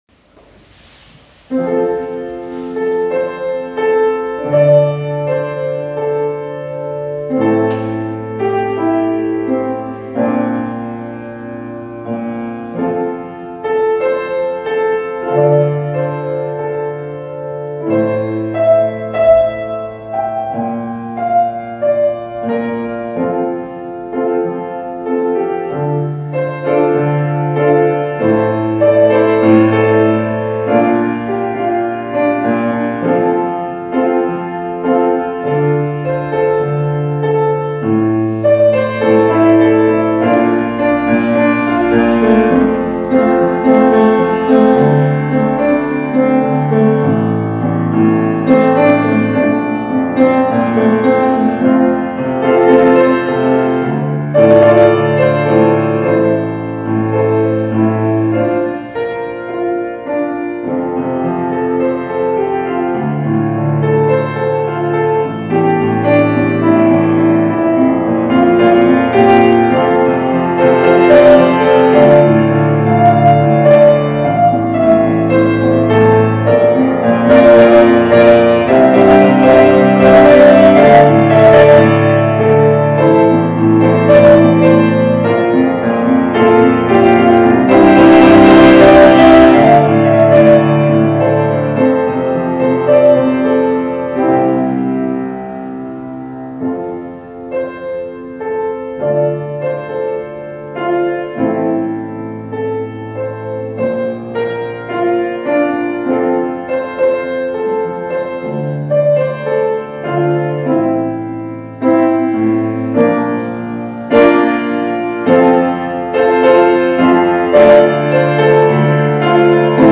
Gottesdienst aus der reformierten Erlöserkirche, Wien-Favoriten, 28.